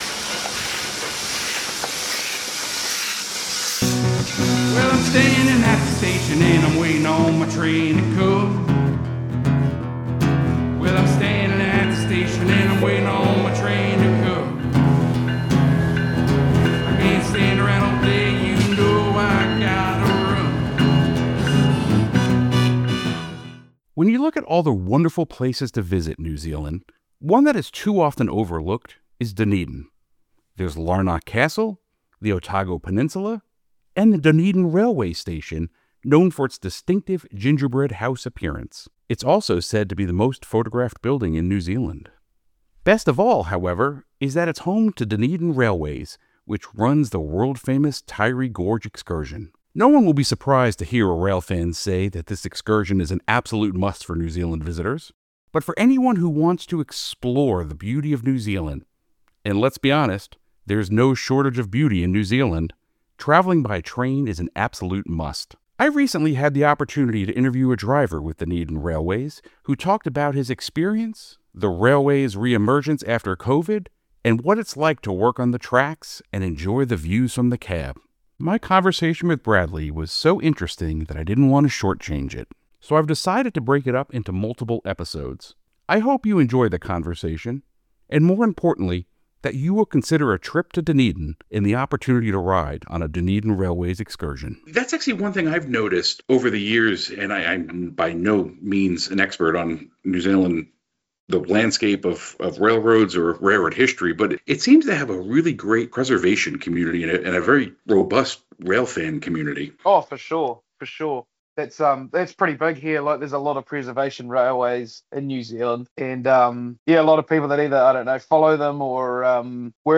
A Conversation With a Dunedin Railways Driver (Part I)
Show Notes This conversation snippet has been slightly edited.